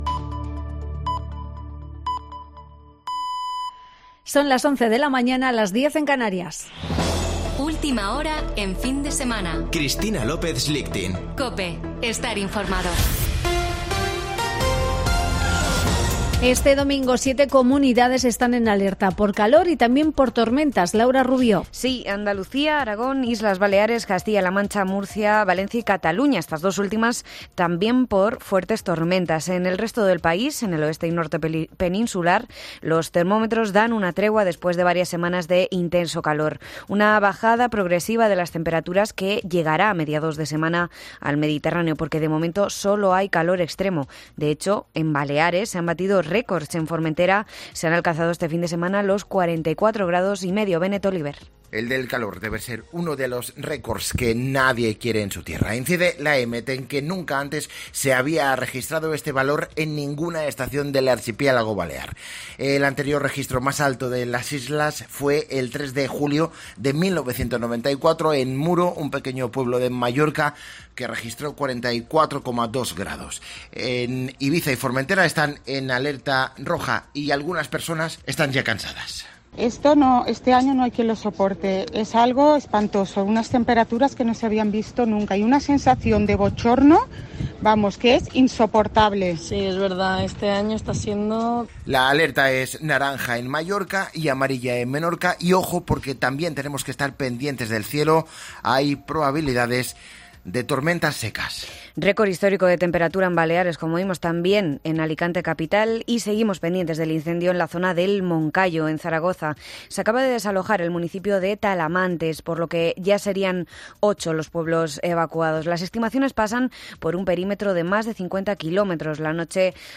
Boletín de noticias de COPE del 14 de agosto de 2022 a las 11.00 horas